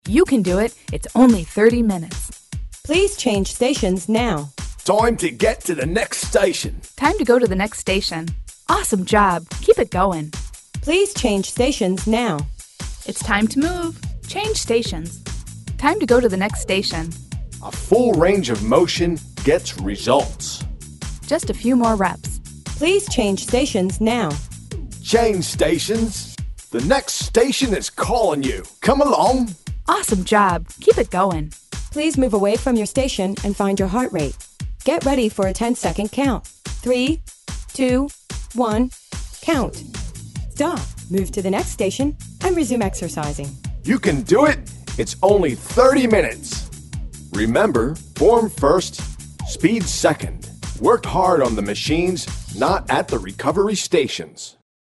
All our Cue CDs are studio recorded and work great on all 30 minute style fitness circuits.
Super Cue: The latest Cue with a fun mixture of Male & Female prompts, fun novelty, wacky voice accents, motivational comments & Heart Rate Check about every 8-10 min depending on interval segments.
The "previews" have music background for reference.
Male & Female Voices + Wacky Accents